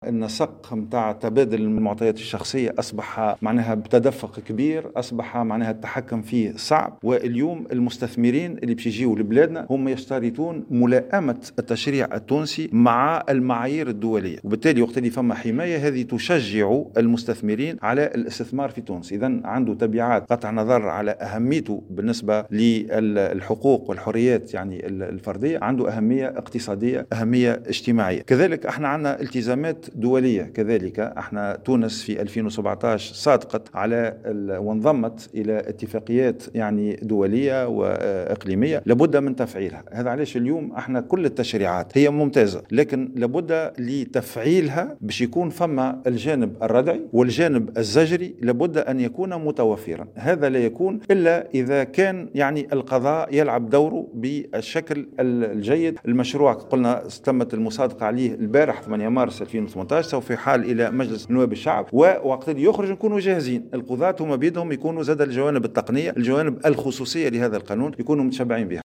Dans une déclaration accordée au correspondant de Jawhara FM, le ministre a indiqué que les investisseurs étrangers appellent souvent à ce que la législation tunisienne en matière de protection des données personnelles soit conforme aux critères internationaux.